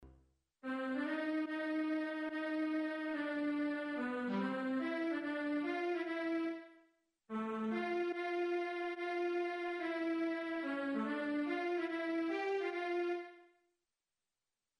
次の第二主題は面白い事にｃ　mollである。